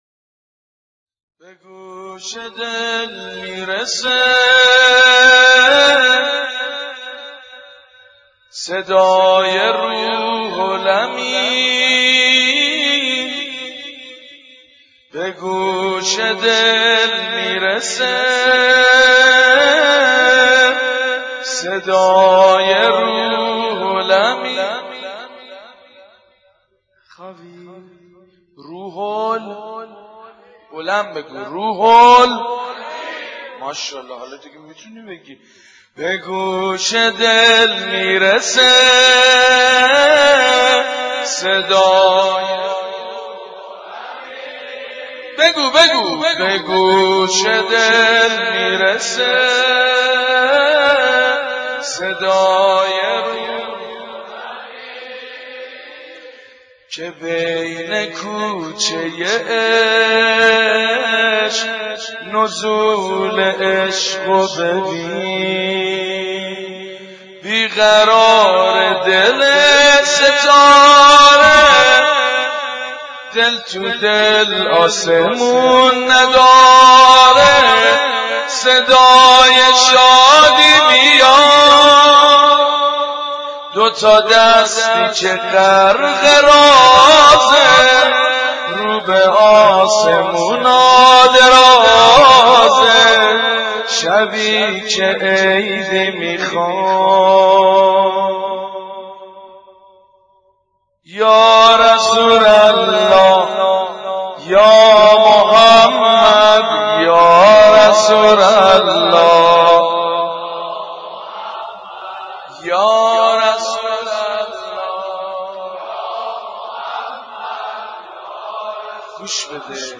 حاج سید مجید بنی فاطمه/به گوش دل می رسه صدای روح الامین
مولودی خوانی حاج سید مجید بنی فاطمه در جشن هفدهم ربیع الاول